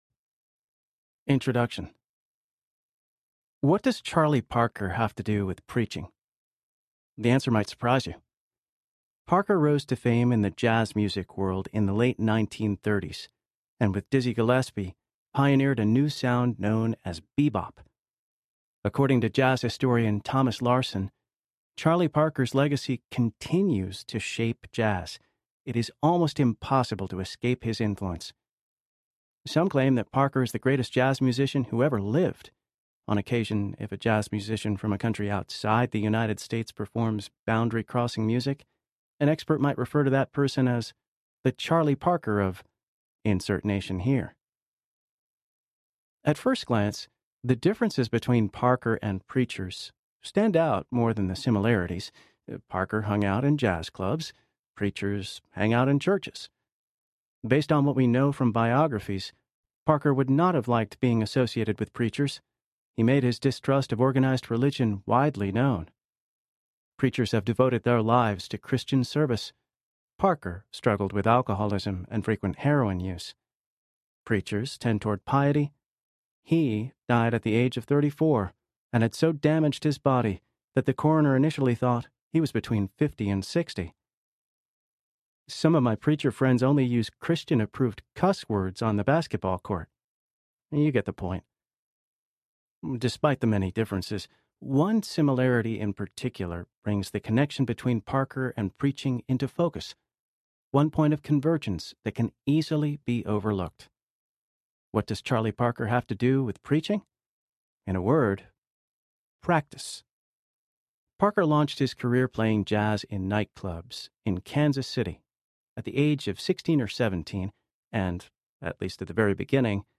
The Practices of Christian Preaching Audiobook
7.5 Hrs. – Unabridged